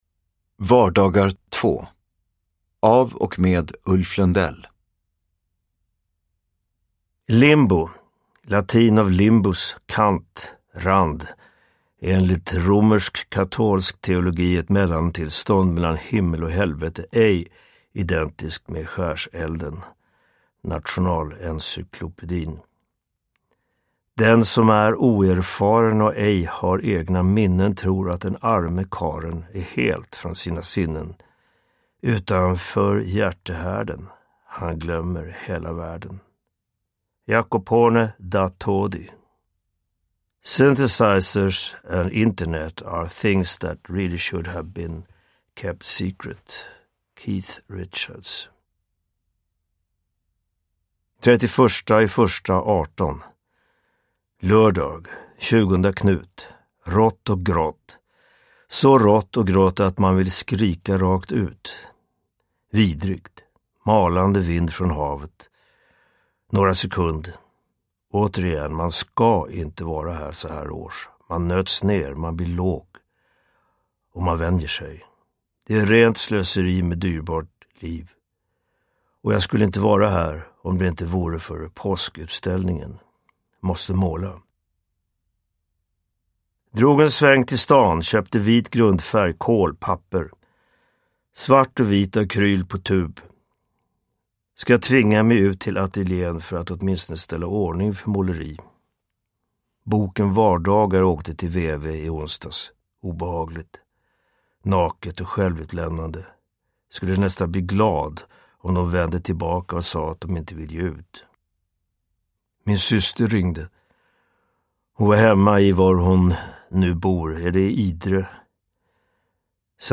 Uppläsare: Ulf Lundell
Ljudbok